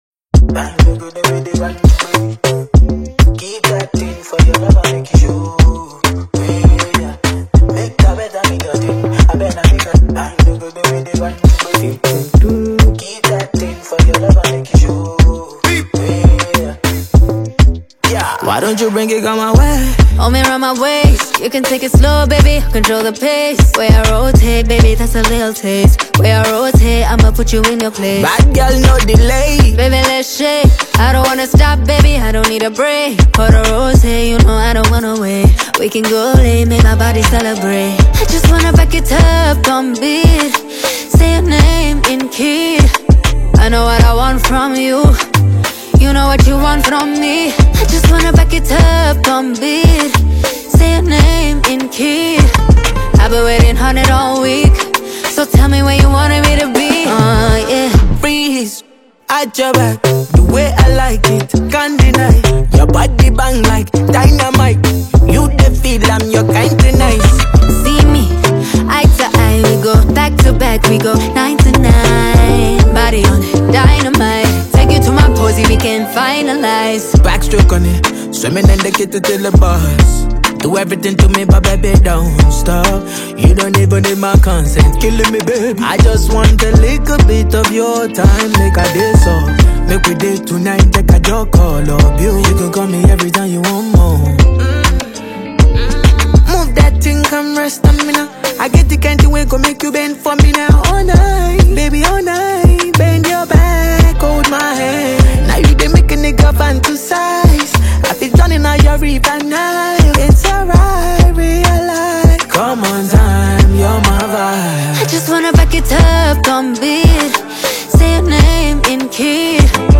Rooted in the Afropop genre
heartfelt love song